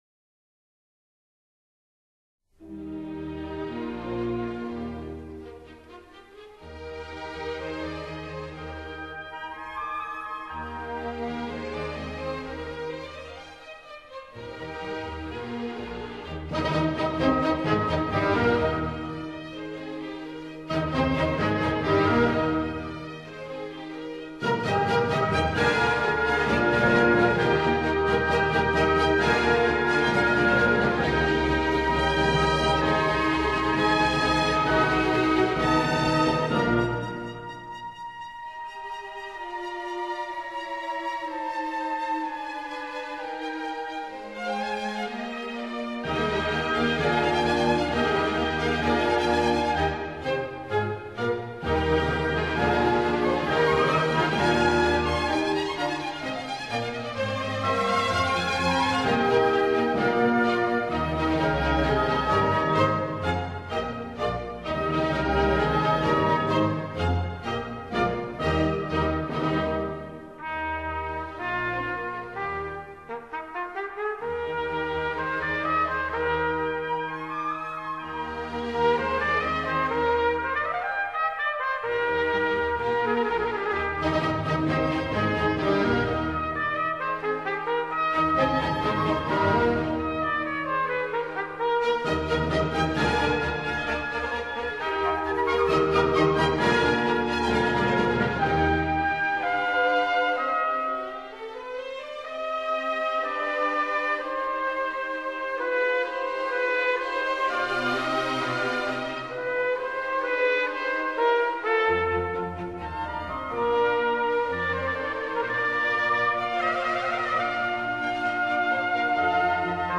古典小號協奏曲